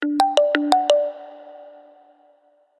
poweron-sound.ogg